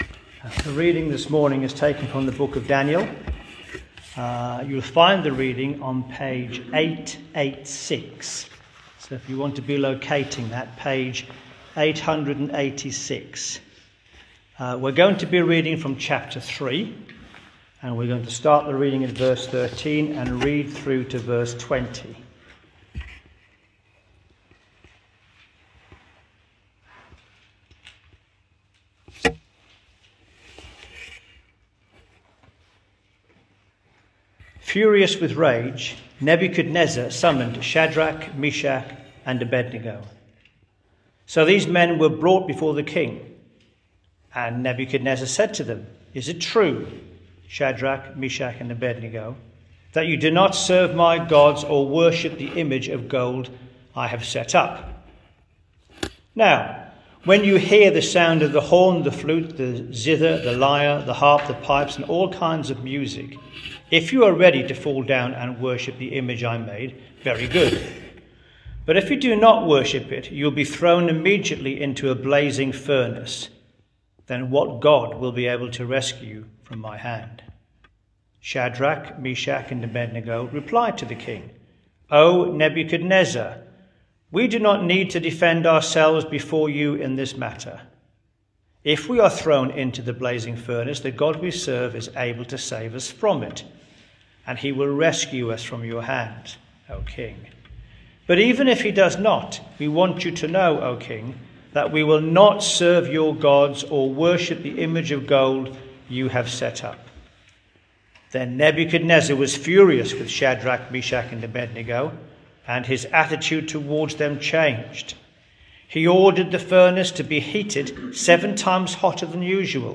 Daniel Passage: Daniel 3:13-20 Service Type: Thursday 9.30am Topics